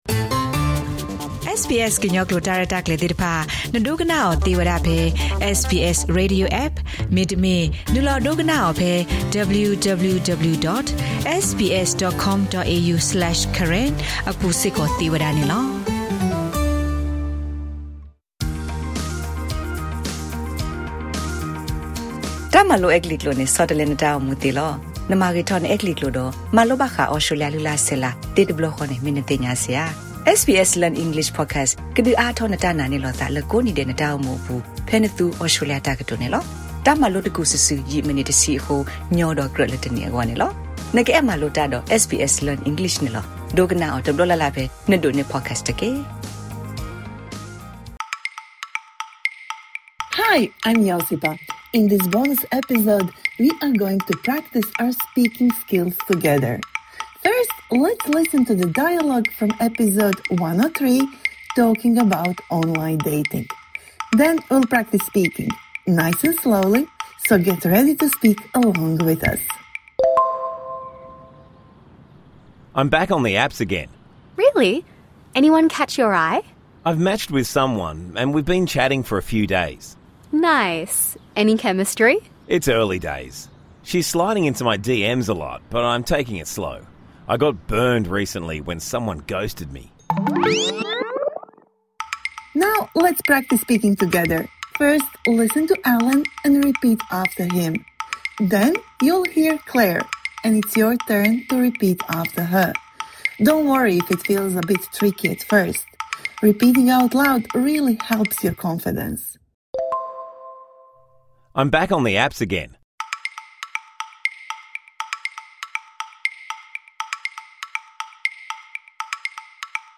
This bonus episode provides interactive speaking practice for the words and phrases you learnt in #103 Talking about online dating (Med)